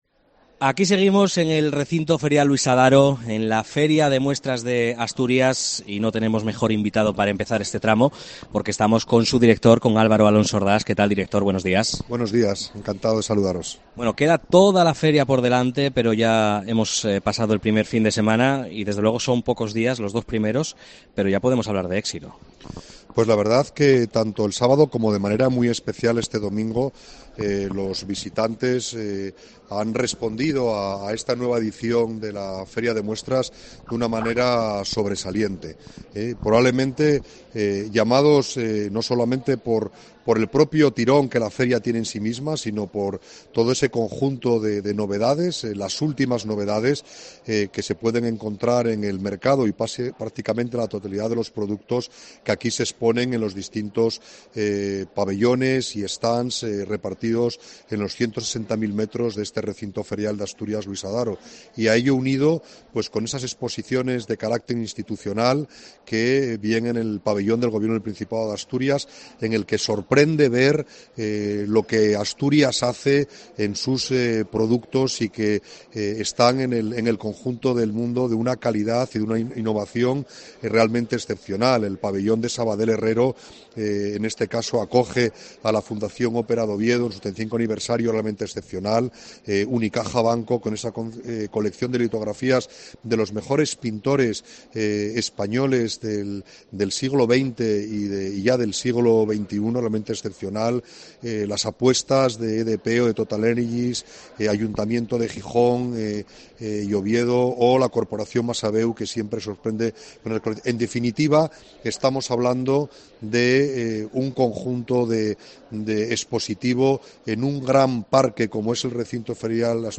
FIDMA 2023: entrevista